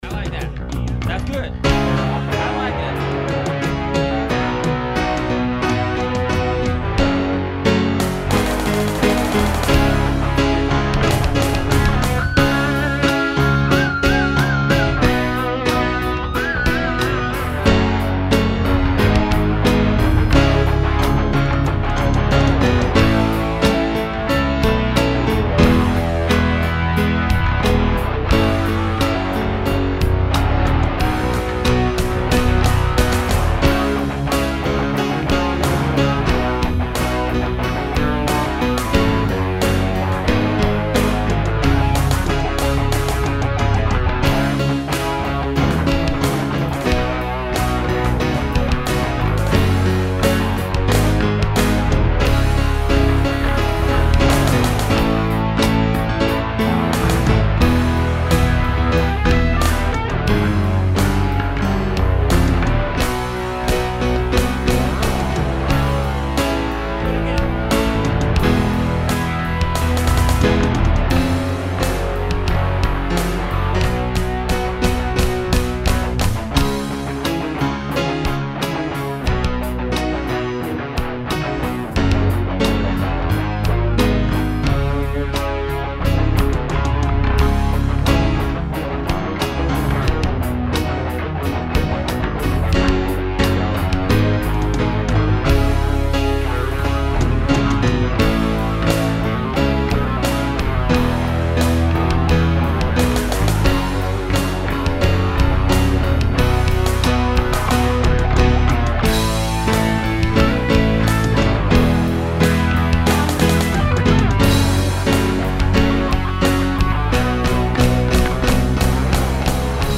A rare ballad from the band.